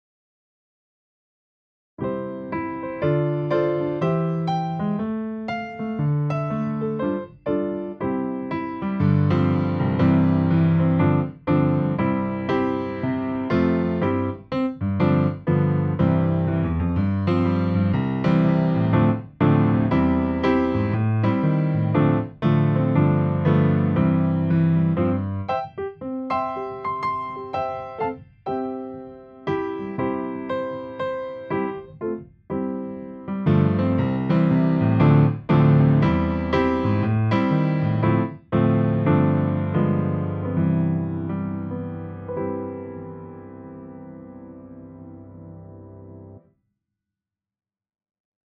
Backing - Engelsk